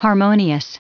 Prononciation du mot harmonious en anglais (fichier audio)
harmonious.wav